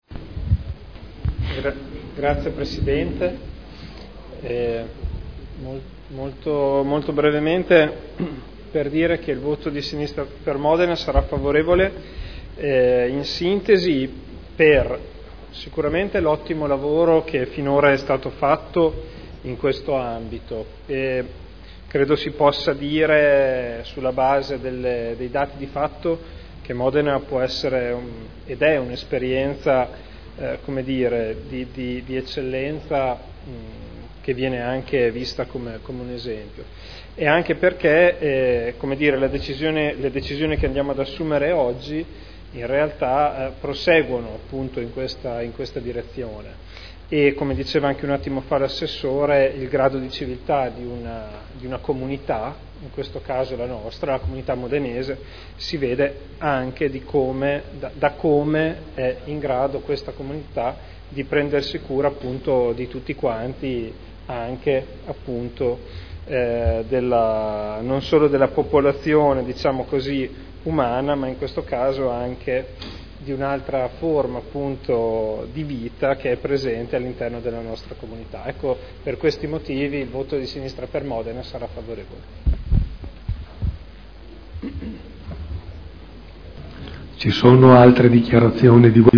Federico Ricci — Sito Audio Consiglio Comunale
Seduta del 01/10/2012 Dichiarazione di Voto.